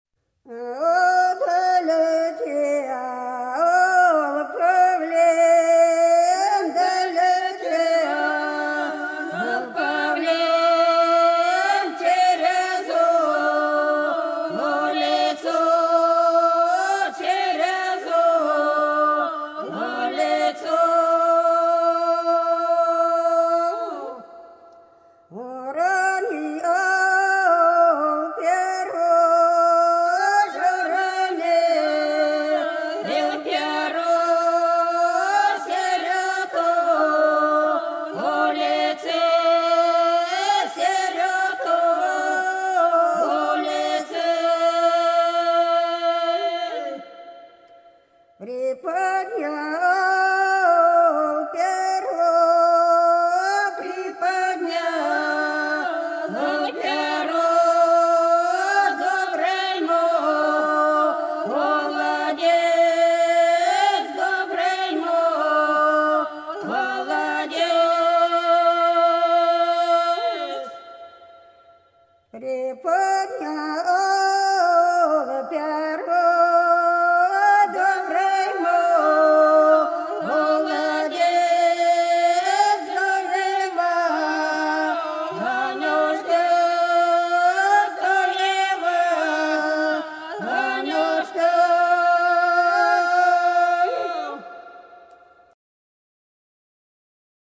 Долина была широкая (Поют народные исполнители села Нижняя Покровка Белгородской области) Летел павлин через улицу - рекрутская